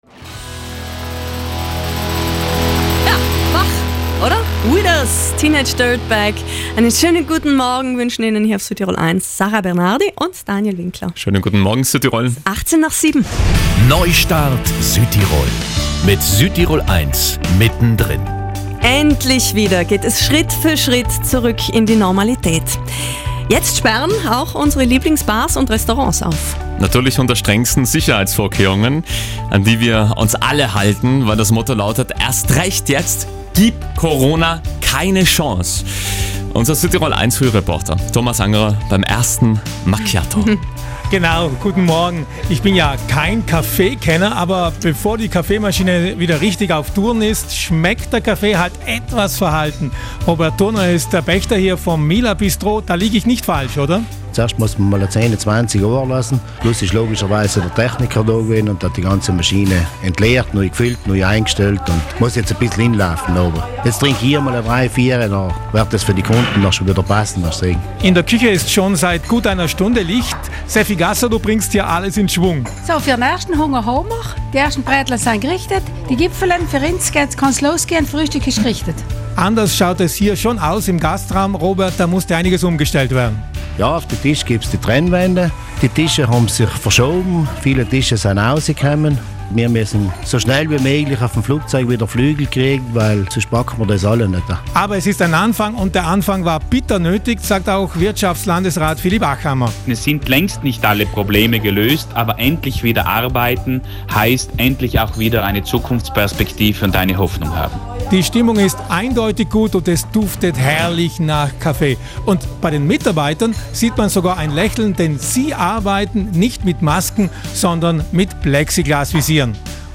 hat im Bistro MILA vorbeigeschaut.